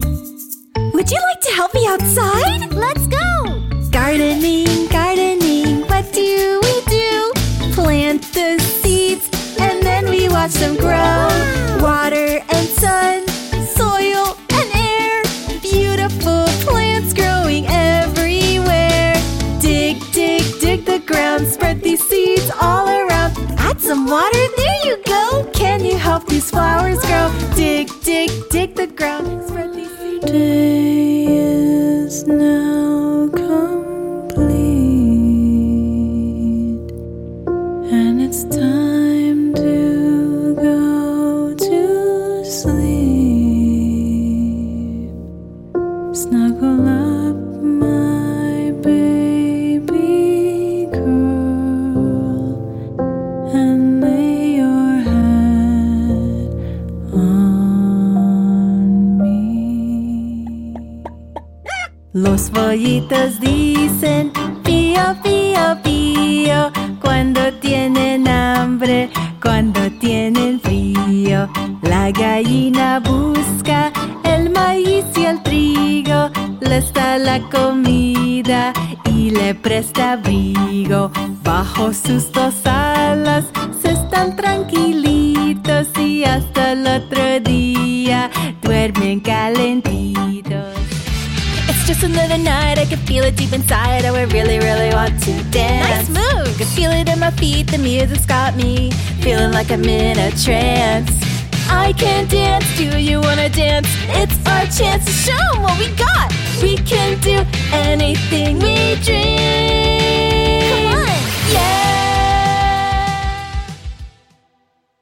Singing Vocal Range: Low C to High F | Tone: Medium, versatile
ANIMATION 🎬
broadcast level home studio